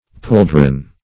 pouldron - definition of pouldron - synonyms, pronunciation, spelling from Free Dictionary Search Result for " pouldron" : The Collaborative International Dictionary of English v.0.48: Pouldron \Poul"dron\, n. See Pauldron .